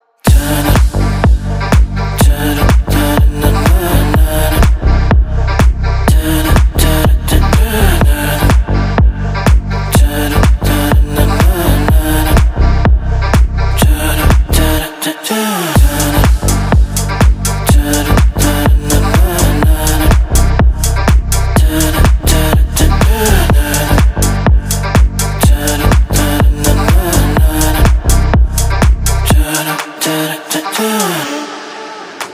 поп
R&B